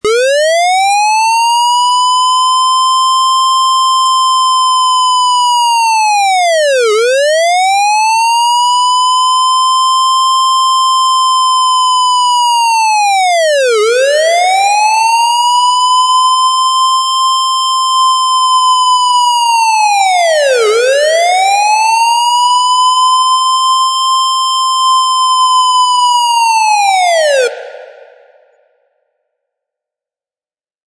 Siren Police - Bouton d'effet sonore